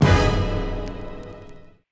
DreChron Orch Hit.wav